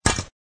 shovel.ogg